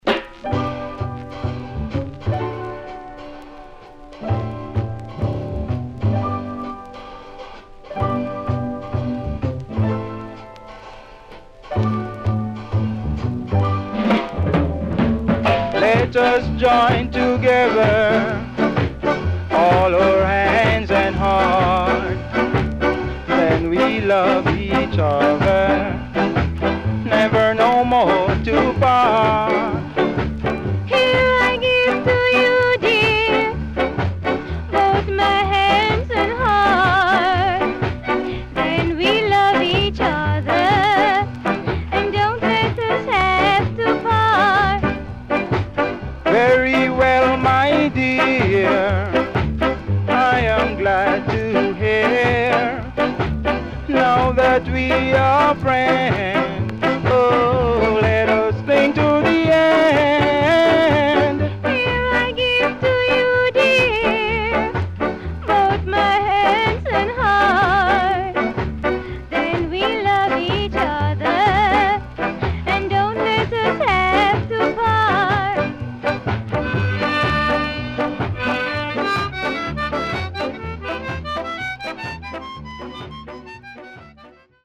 Rare.Nice Duet Ska
SIDE A:プレス起因？で所々チリノイズ入ります。